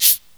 Shaker
Original creative-commons licensed sounds for DJ's and music producers, recorded with high quality studio microphones.
natural-shaker-hi-hat-f-sharp-key-21-sh4.wav